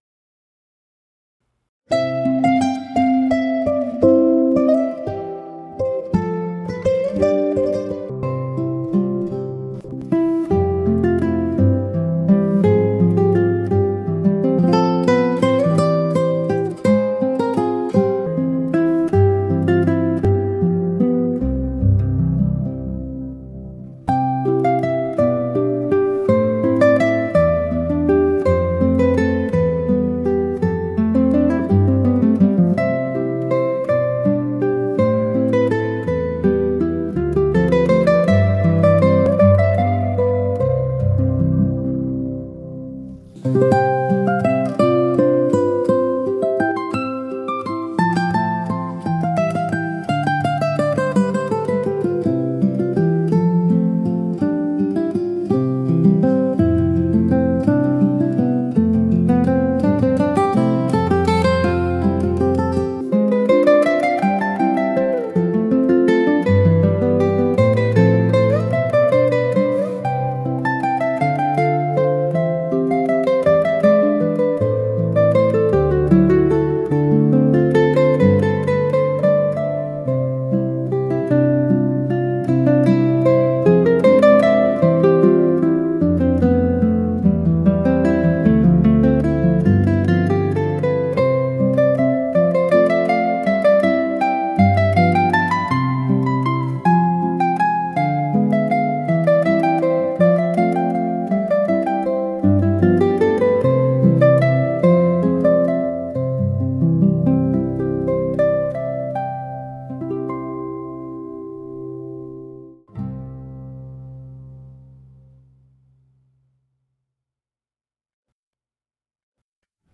solo Classical Guitar